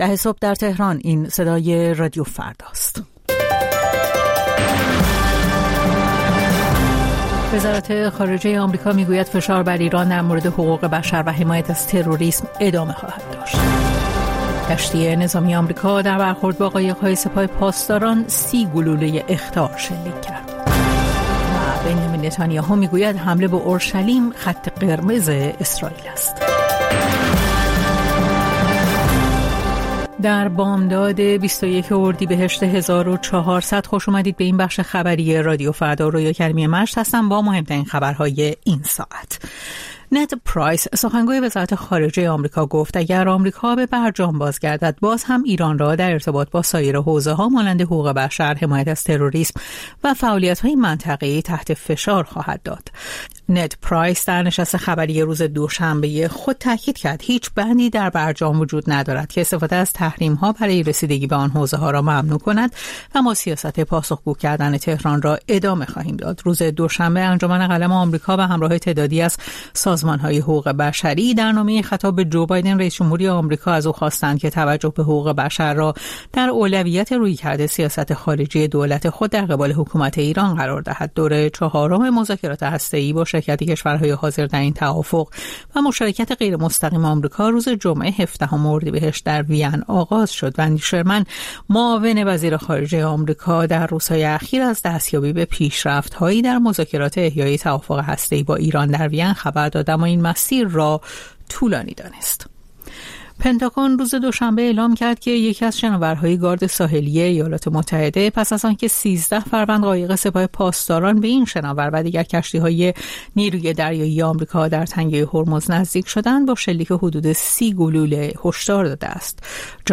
پخش زنده - پخش رادیویی